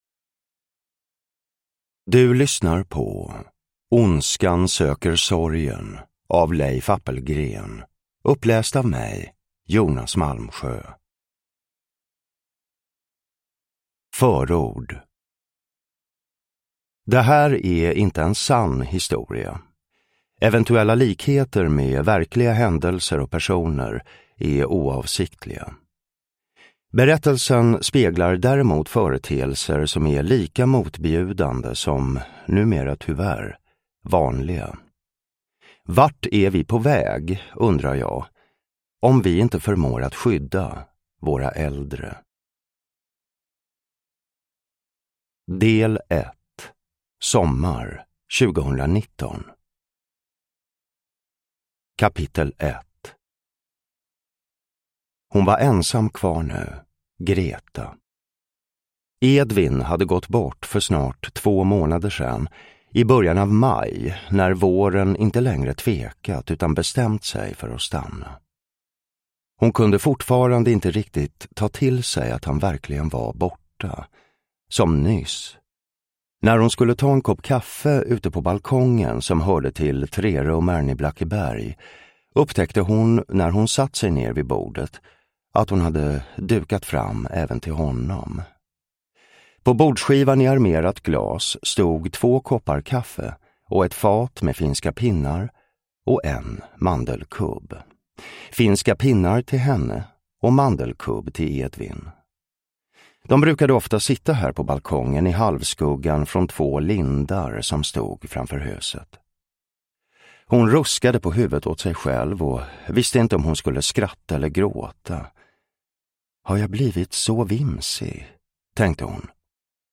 Ondskan söker sorgen – Ljudbok
Uppläsare: Jonas Malmsjö